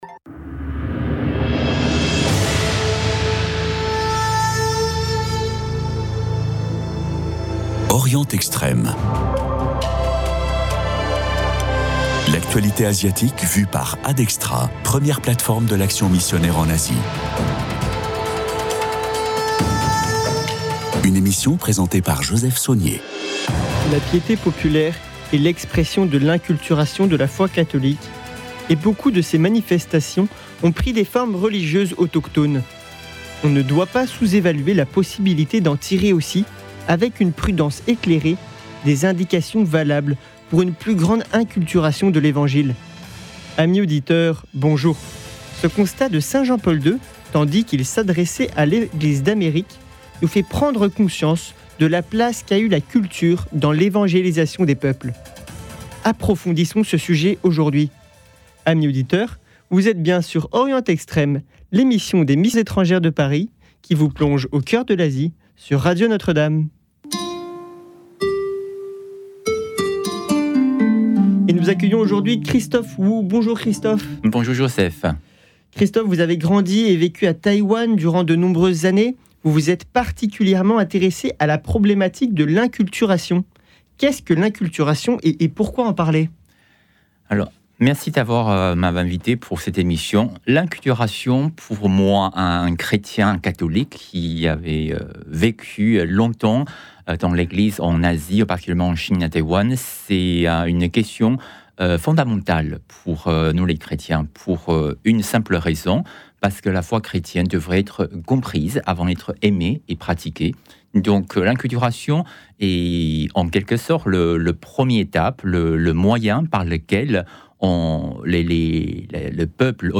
Chaque émission est un focus sur un pays avec une problématique choisie avec l’invité du jour. Le journal de la semaine est préparé à partir des dépêches d’Eglise d’Asie et de l’actualité des derniers jours.